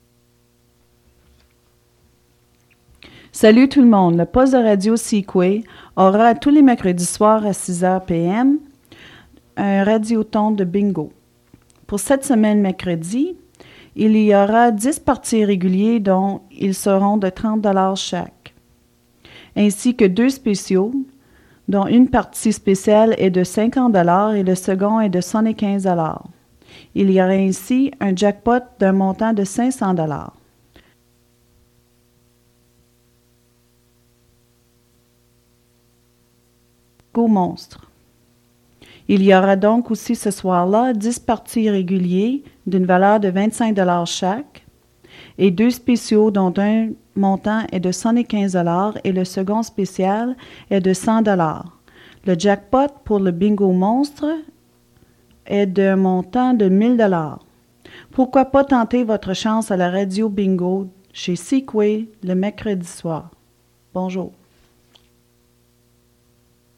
Fait partie de Bingo announcement